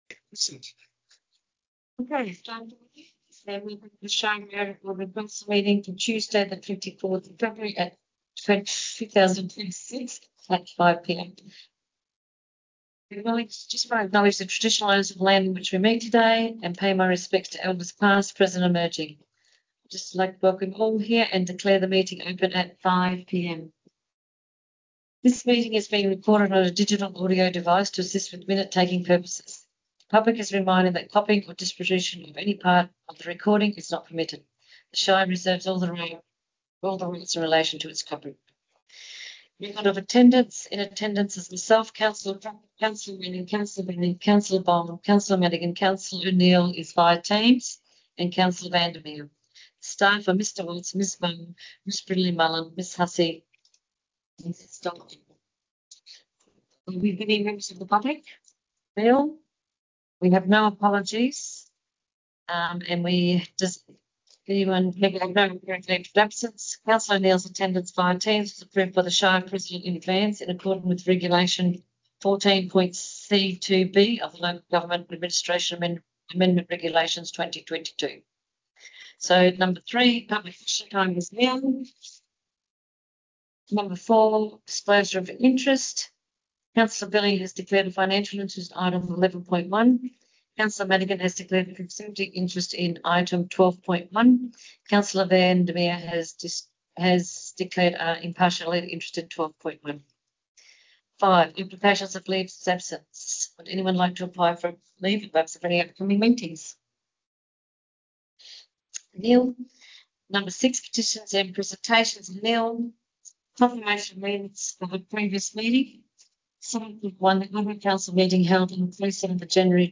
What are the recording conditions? February Ordinary Council Meeting » Shire of Merredin Location: Council Chambers